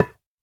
Minecraft Version Minecraft Version snapshot Latest Release | Latest Snapshot snapshot / assets / minecraft / sounds / block / bone_block / break3.ogg Compare With Compare With Latest Release | Latest Snapshot
break3.ogg